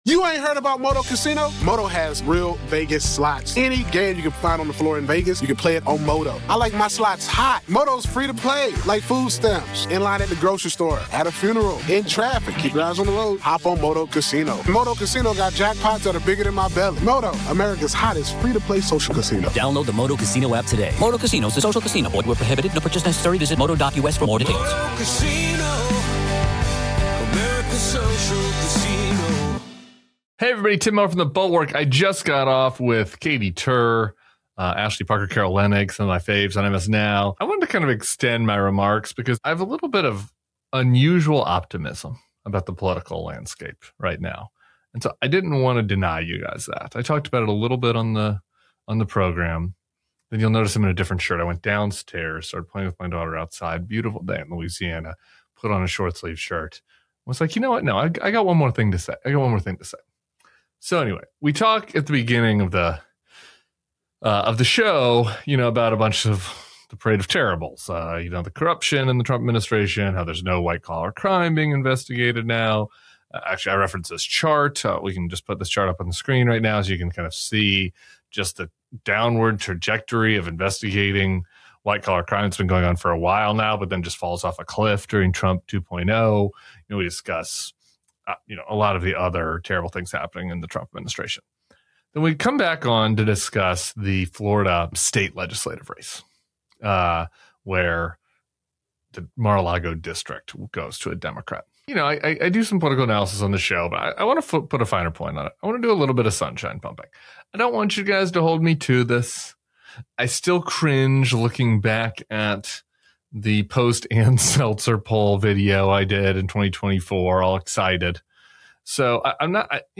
Tim Miller was on with Katy Tur to take on a surprising shift in Florida politics after Democrats flip a seat in Trump’s own backyard.